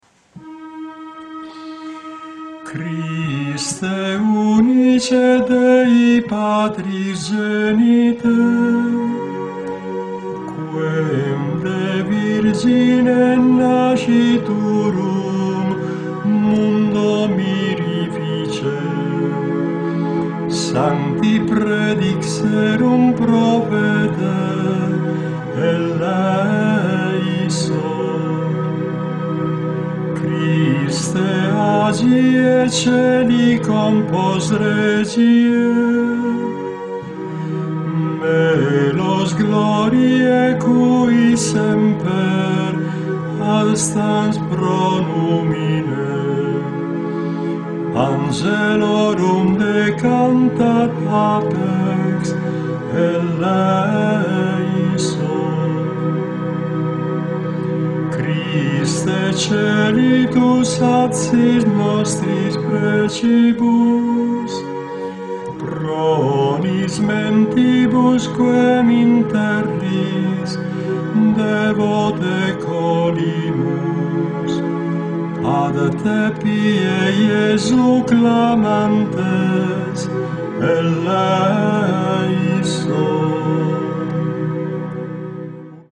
Kyrie (3 volte) — Christe (3 volte) — Kyrie (2 volte) | Kyrie (1 volta) – voce.